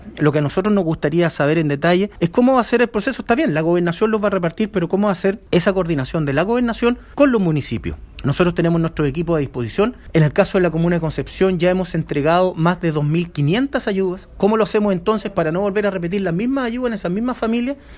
El alcalde de Concepción, Álvaro Ortiz, aseguró que están dispuestos a apoyar la entrega de alimentos, por lo mismo, espera que haya una coordinación con las gobernaciones, para entregar de manera justa y equitativa esta ayuda.